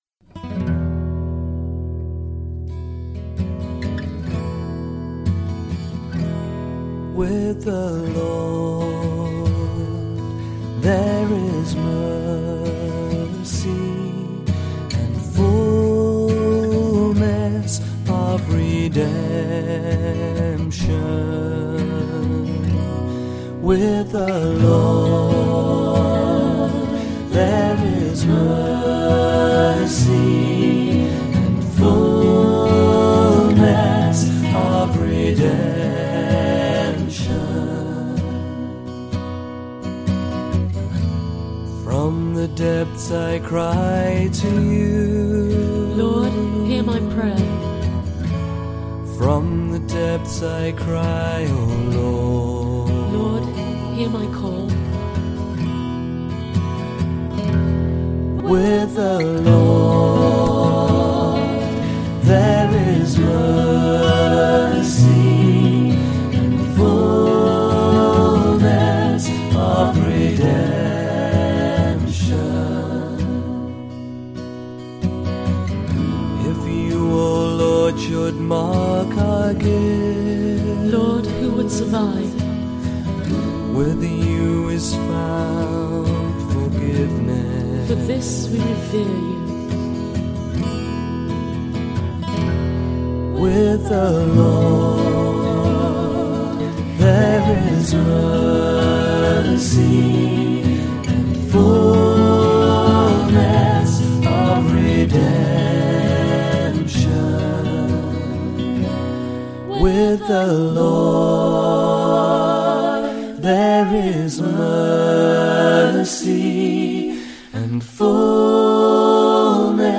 Responsorial Psalm for the 5th Sunday of Lent